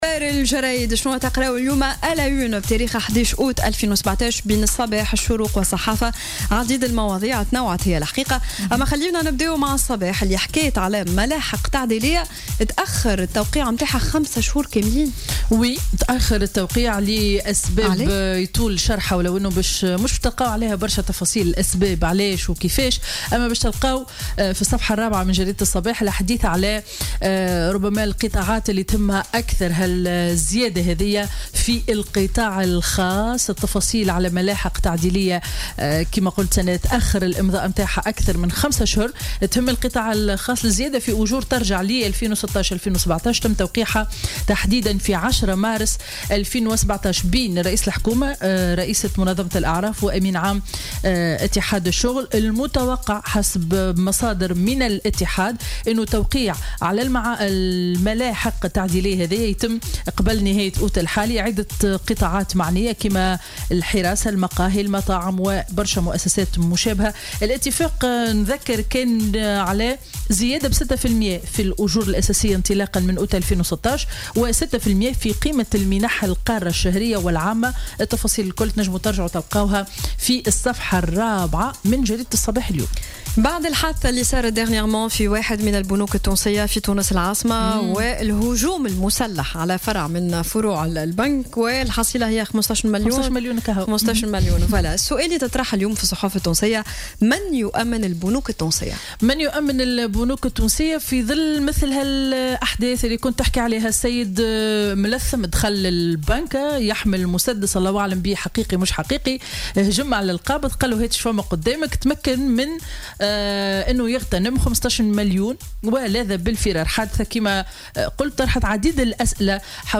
Revue de presse du vendredi 11 août 2017